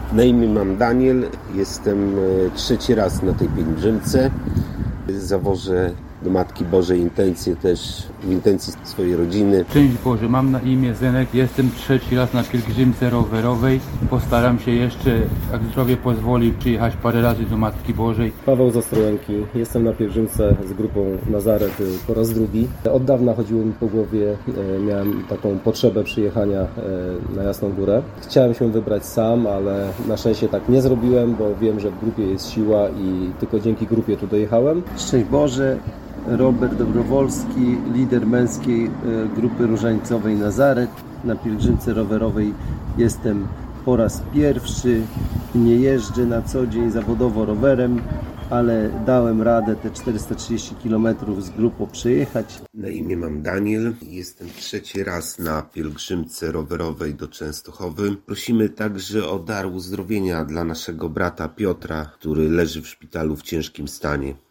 Oto, co pątnicy powiedzieli w rozmowie z Radiem Nadzieja: